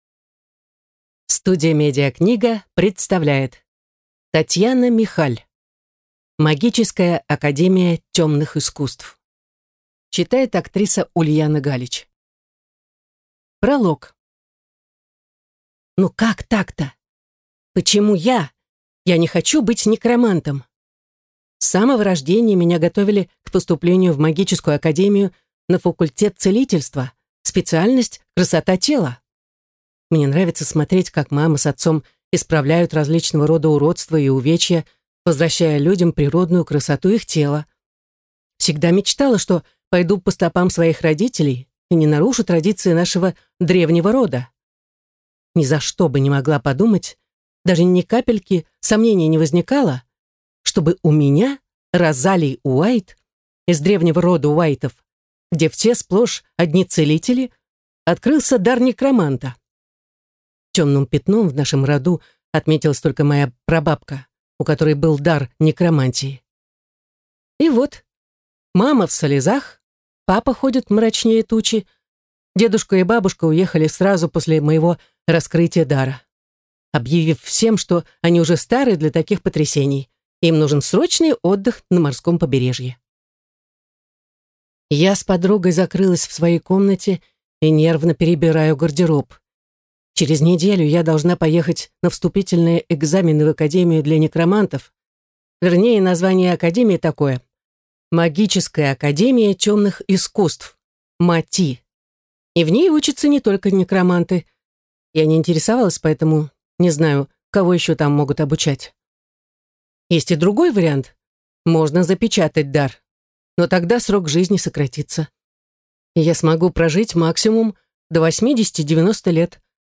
Аудиокнига Магическая академия темных искусств | Библиотека аудиокниг